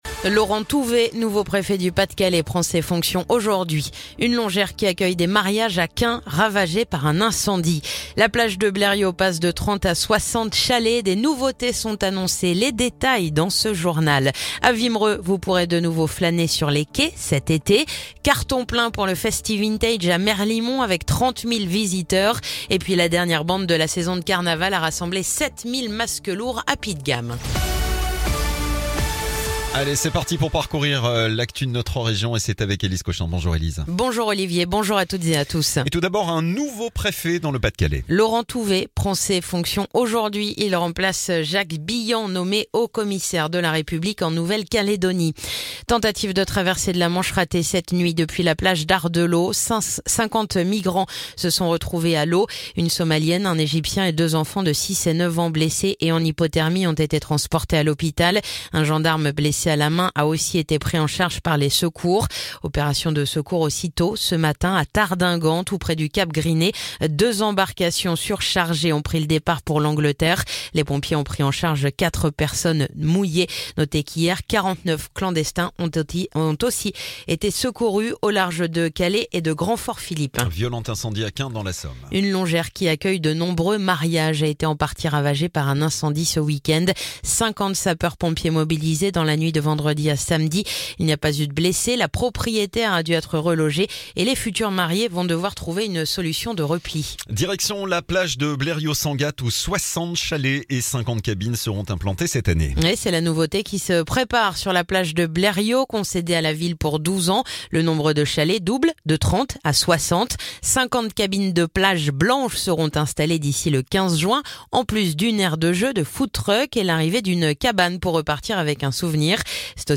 Le journal du lundi 28 avril